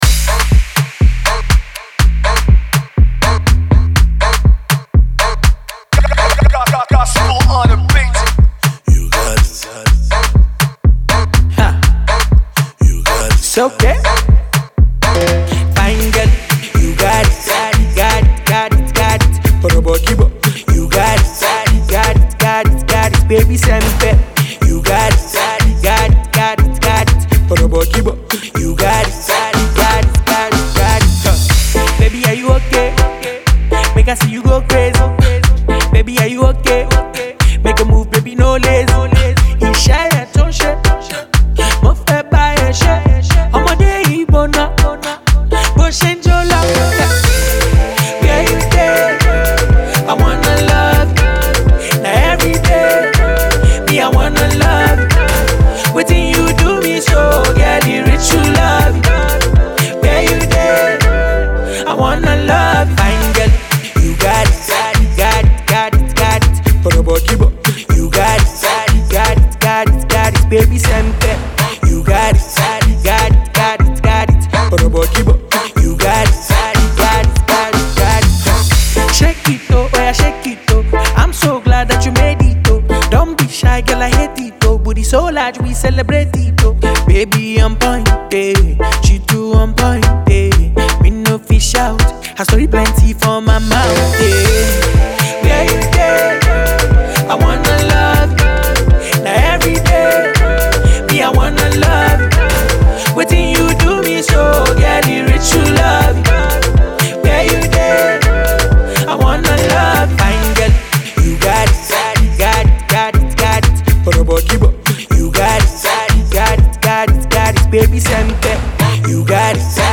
monstrous dance smash